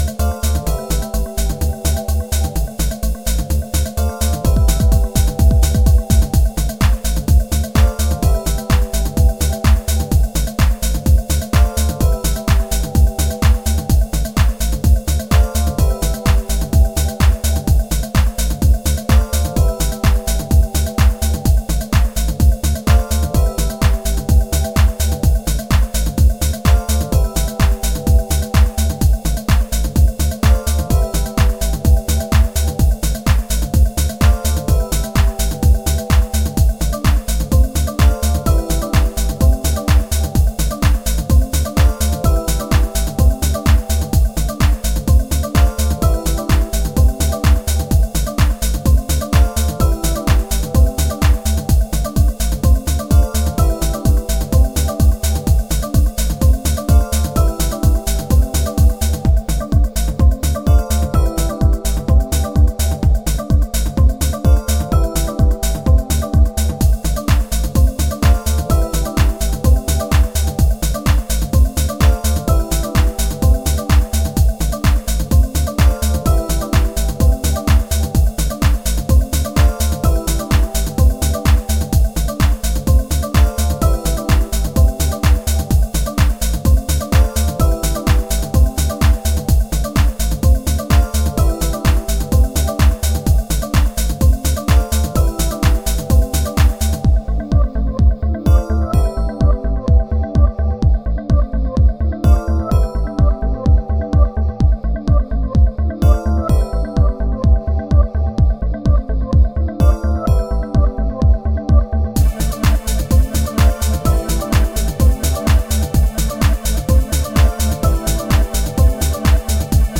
techno sound
fusion of string chords, reverse synth tricks & arpeggiation